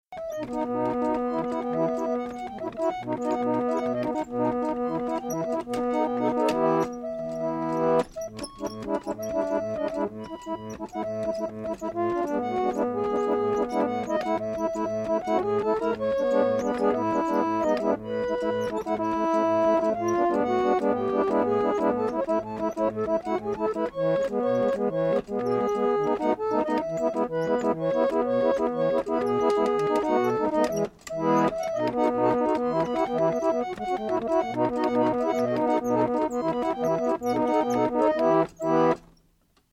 Antiphonel
Unknown Tunes Played by Debain Antiphonel
Debain Antiphonel mounted atop a Debain Harmonium of circa 1850s.